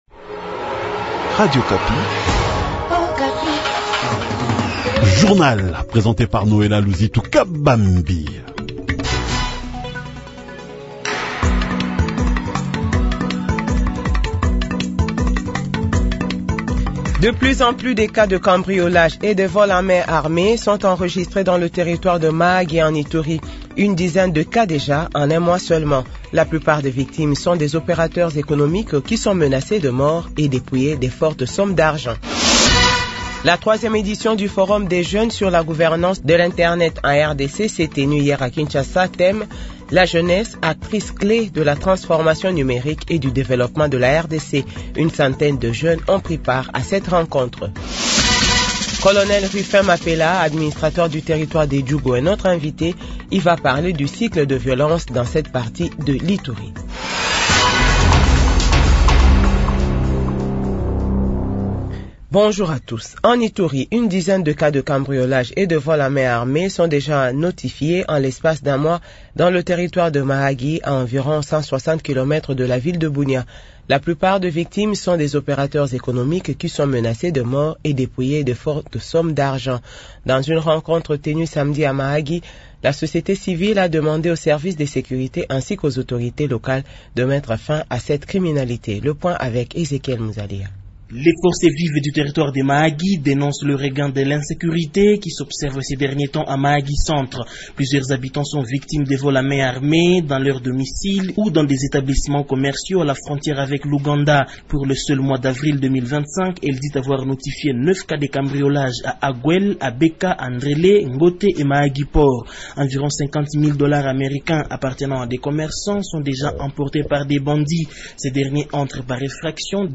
Journal 8h